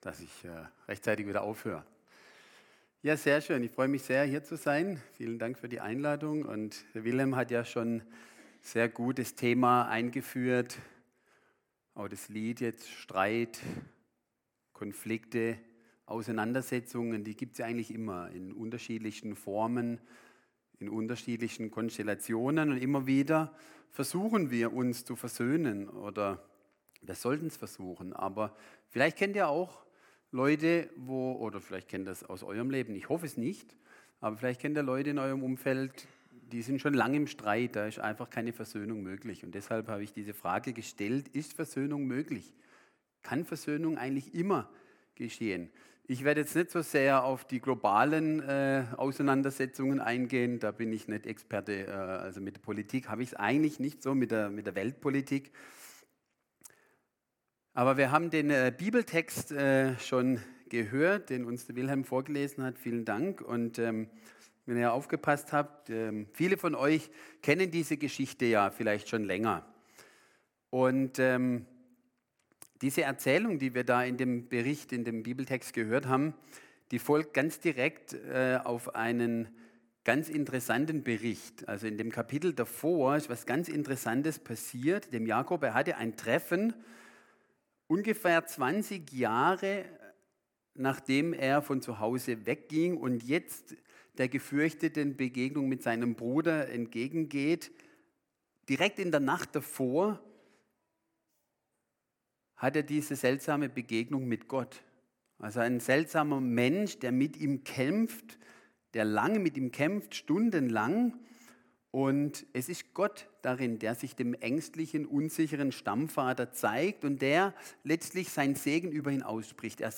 Go In Gottesdienst am 16.06.2024